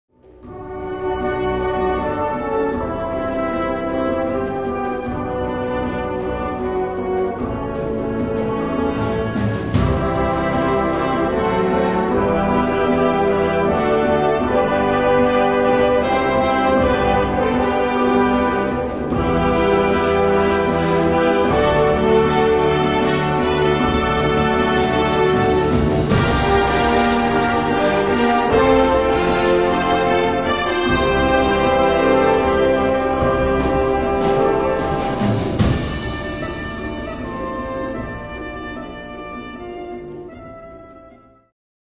Gattung: Konzertwerk
Besetzung: Blasorchester
Chorstimmen sind im Werk enthalten.